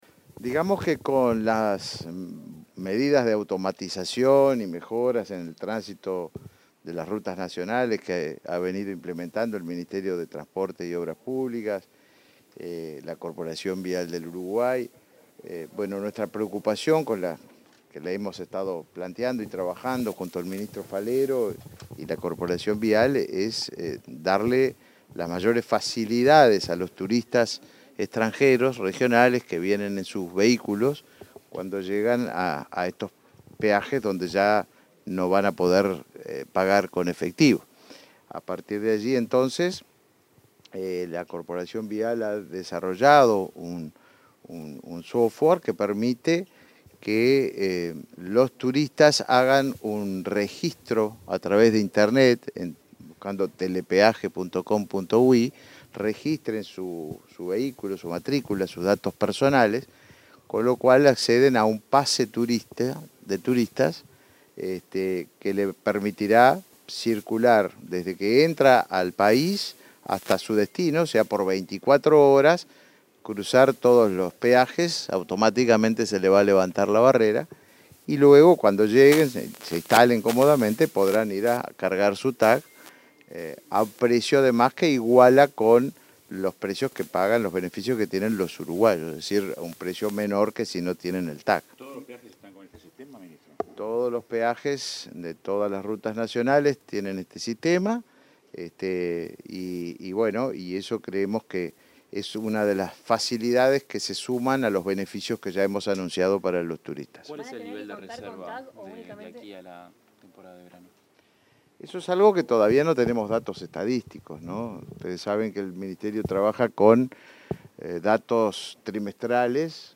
Declaraciones de los ministros de Turismo y Transporte
Declaraciones de los ministros de Turismo y Transporte 07/12/2022 Compartir Facebook X Copiar enlace WhatsApp LinkedIn El ministro de Turismo, Tabaré Viera, y su par de Transporte y Obras Públicas, José Luis Falero, informaron a la prensa sobre los beneficios a turistas extranjeros en los peajes nacionales.